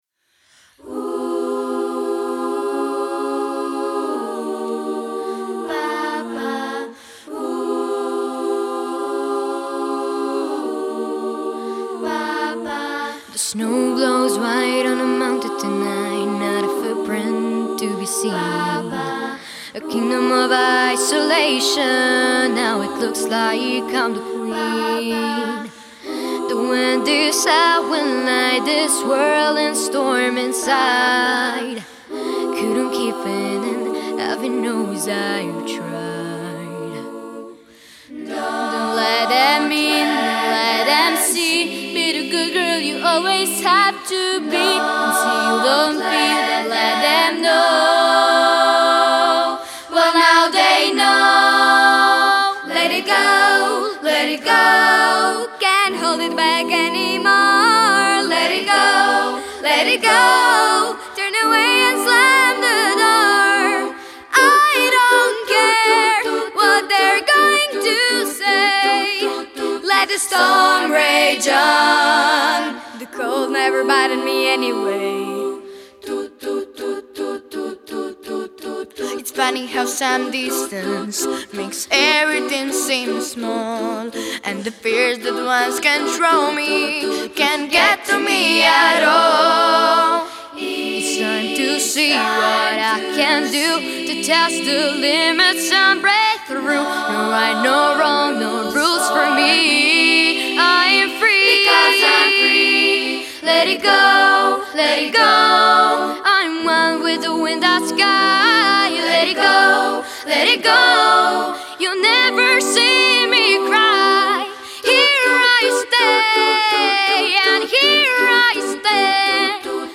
Zbor-OS-Bedekovcina-Let-it-go3.mp3